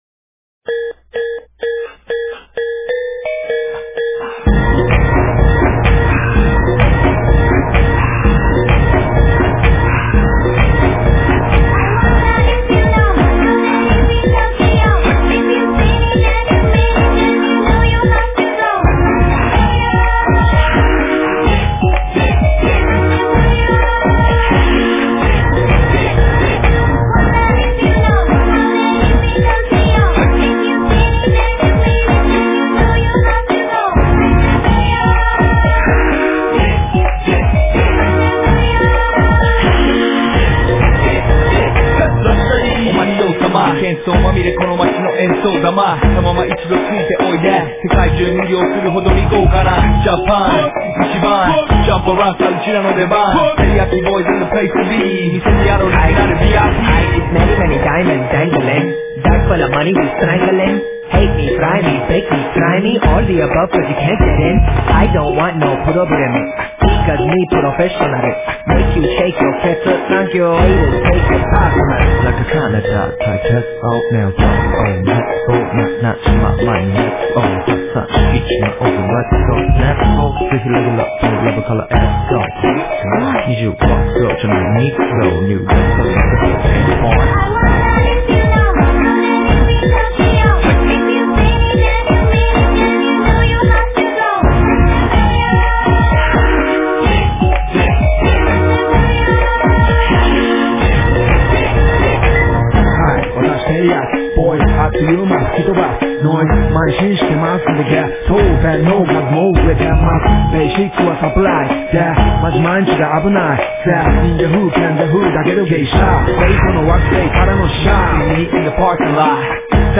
Rap
ремикс 1 песни...